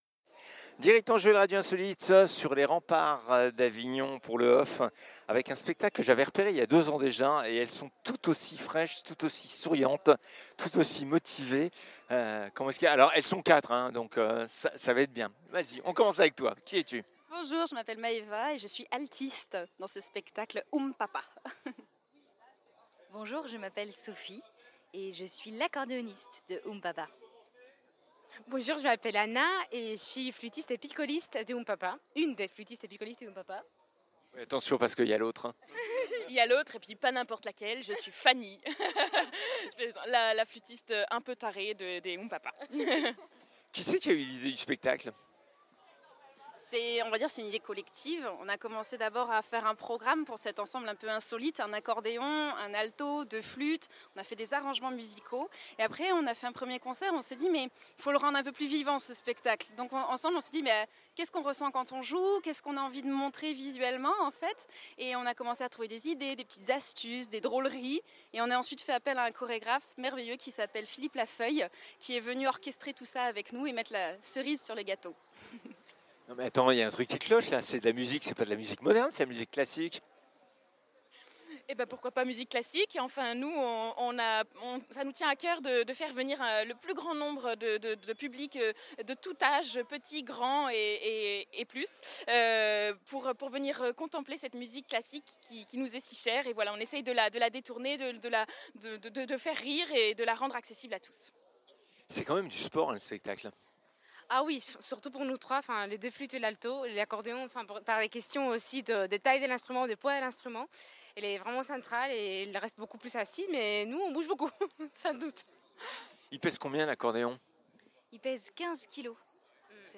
Un véritable orchestre miniature, plein d’humour et de fantaisie !
Dans ce récital de rêve, la technique de haut vol des quatre musiciennes sert des partitions virtuoses, chaleureuses et tendres.
Oum…Pa…Pa… clin d’oeil aux trois temps de la valse, fait virevolter l’accordéon classique à travers une succession de tableaux surprenants, entouré par deux flûtes traversières, un alto, et parfois les piccolos.
De la musique classique à déguster le sourire aux lèvres et l’esprit joyeux !